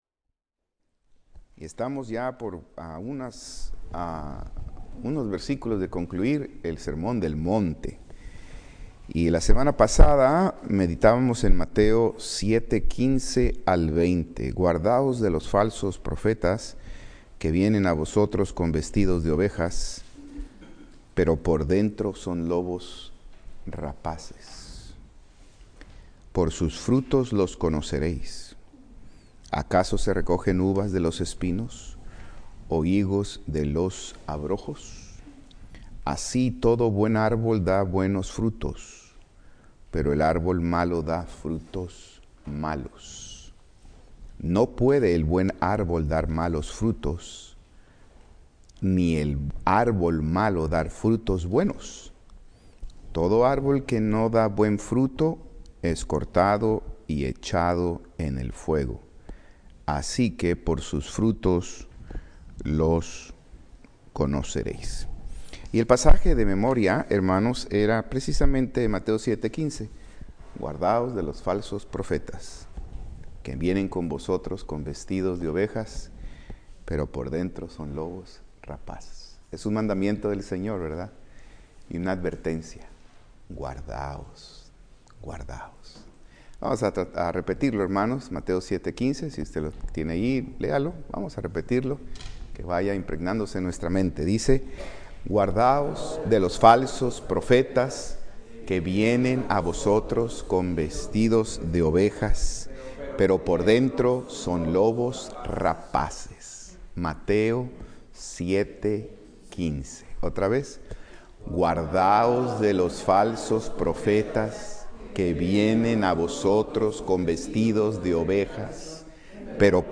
Escuela Dominical